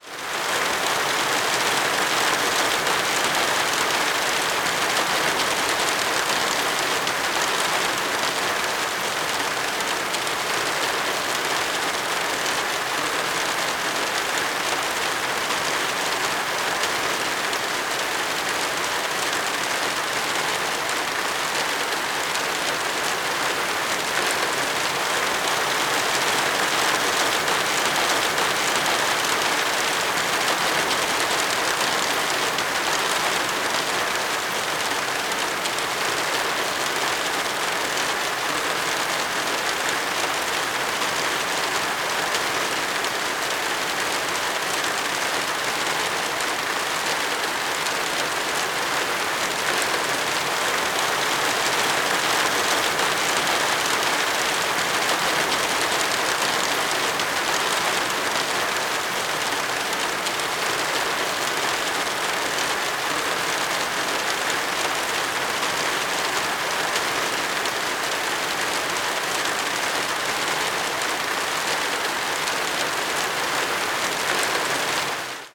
zapsplat_nature_rain_falling_on_verandah_roof_15467